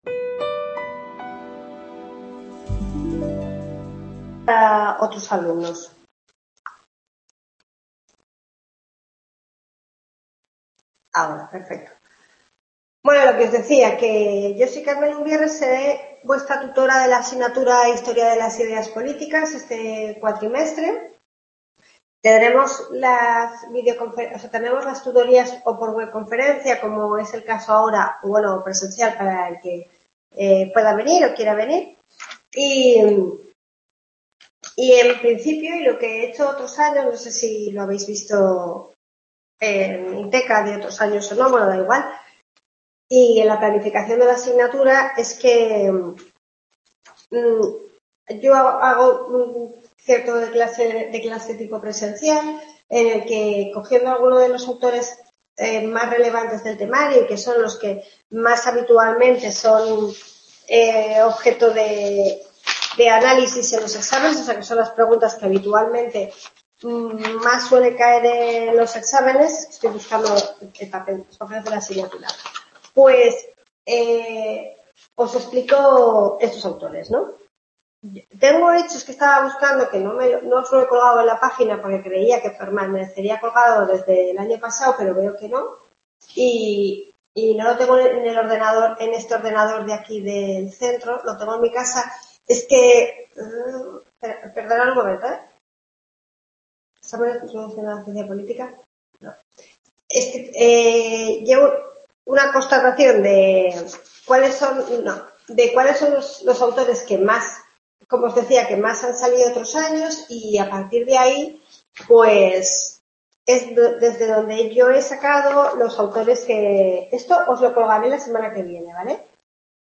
Primera tutoría